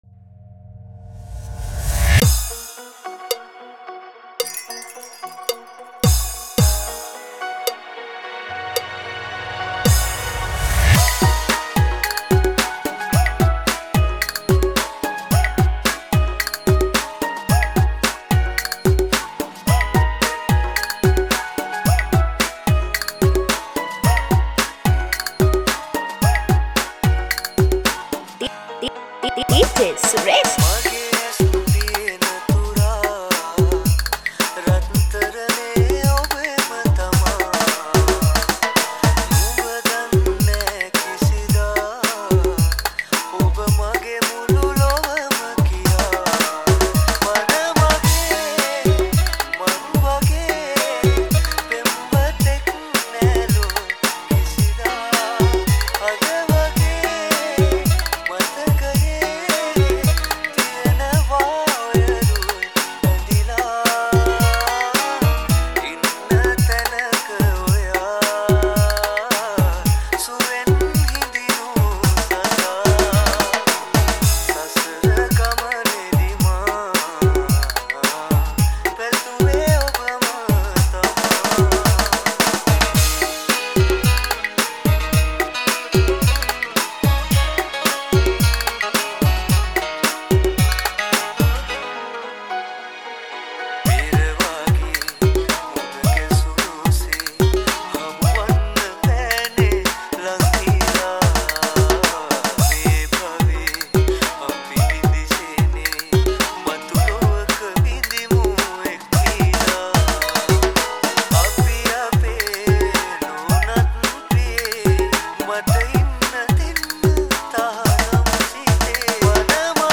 Live Pad Mix